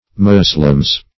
Moslems - definition of Moslems - synonyms, pronunciation, spelling from Free Dictionary
(m[o^]z"l[e^]mz), or collectively Moslem. [Ar. muslim a